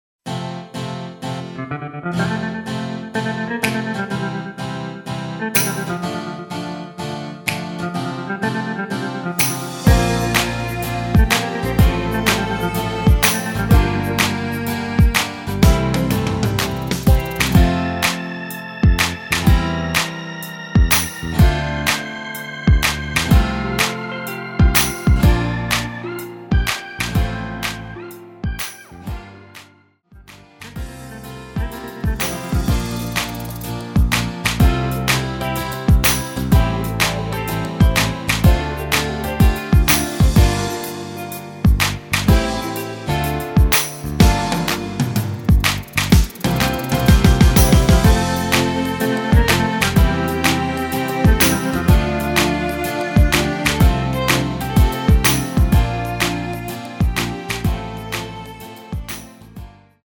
전주 없는 곡이라 전주 만들어 놓았습니다.(기타 3박 끝나고 노래 시작)
◈ 곡명 옆 (-1)은 반음 내림, (+1)은 반음 올림 입니다.
앞부분30초, 뒷부분30초씩 편집해서 올려 드리고 있습니다.
중간에 음이 끈어지고 다시 나오는 이유는